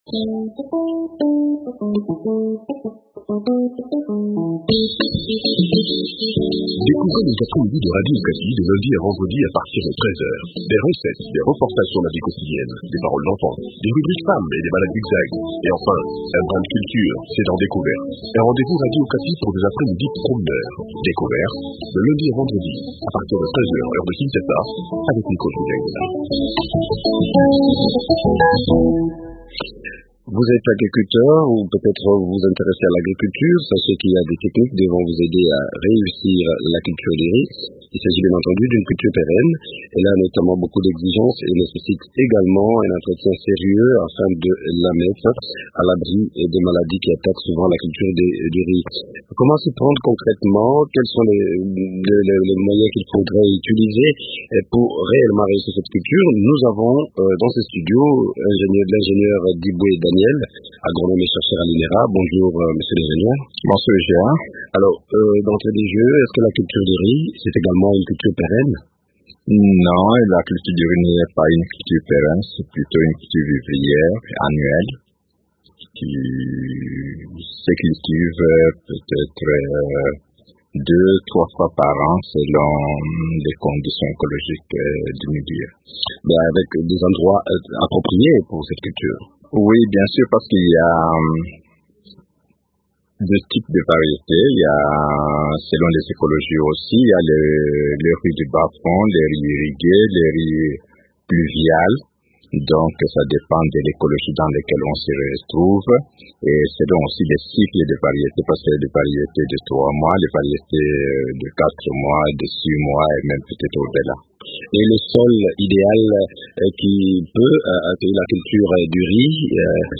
Quels sont alors les principes à respecter pendant la saison culturale? Des détails dans cet entretien